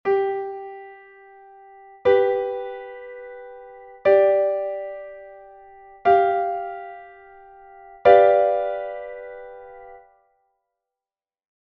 Acordes cuatríadas
O acorde de 7ª de Dominante está formado por unha 3ª M, 5ª X e unha 7ª m.
Partitura formación acorde cuatríada sobre Sol
formacion_cuatriada.mp3